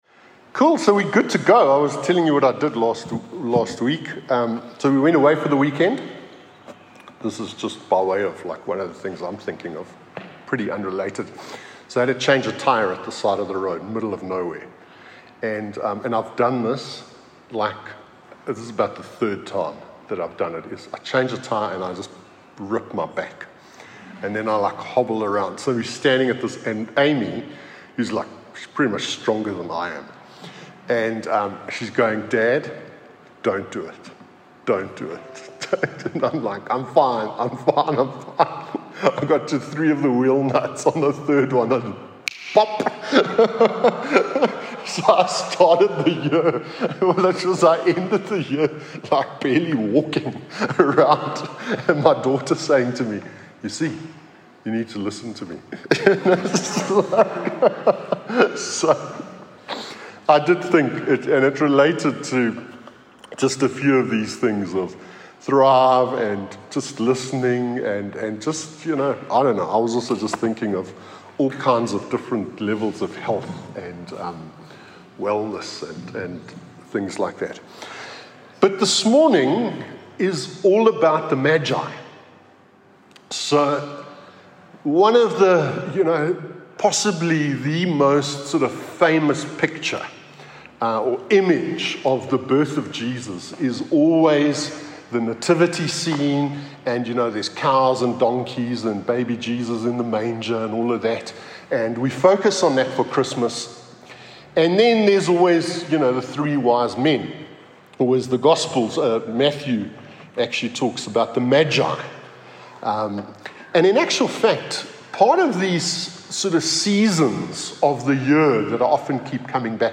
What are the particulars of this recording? From Hillside Vineyard Christian Fellowship. At Aan-Die-Berg Gemeente.